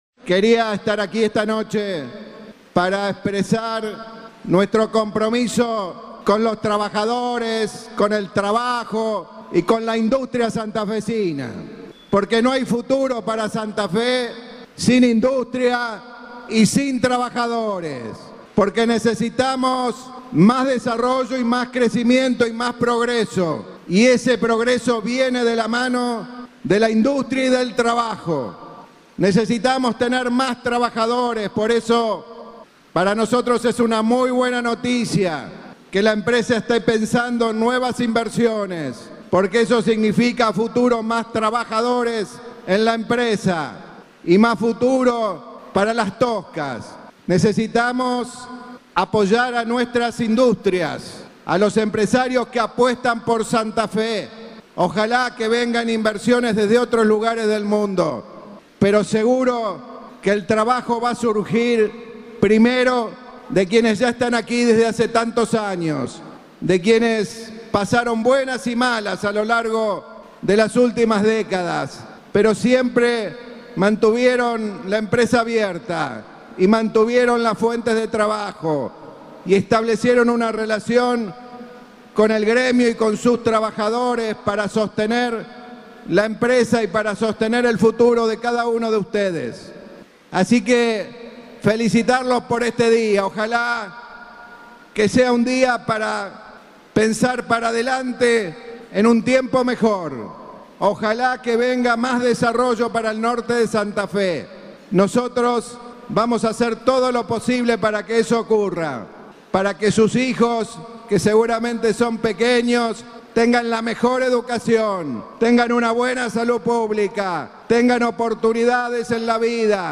El gobernador en la celebración por el Día del Curtidor.